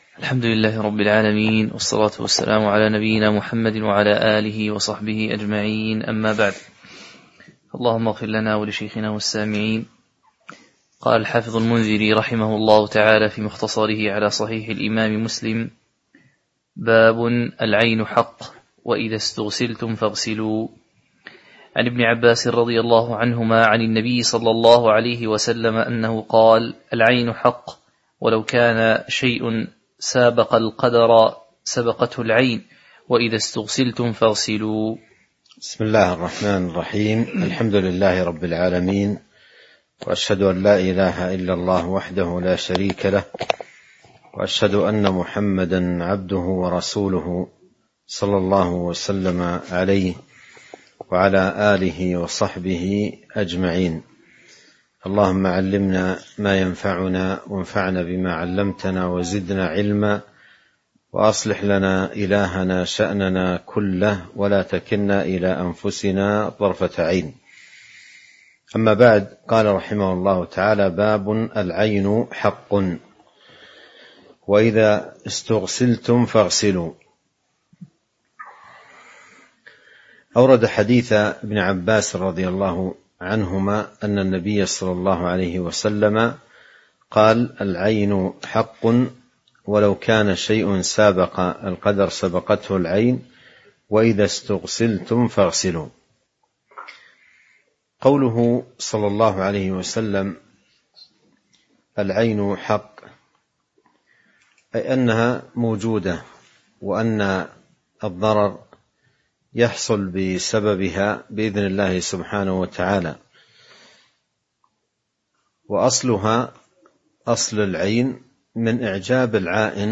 تاريخ النشر ٢٠ رجب ١٤٤٣ هـ المكان: المسجد النبوي الشيخ: فضيلة الشيخ عبد الرزاق بن عبد المحسن البدر فضيلة الشيخ عبد الرزاق بن عبد المحسن البدر باب العين حق وإذا استغسلتم فاغسلوا (03)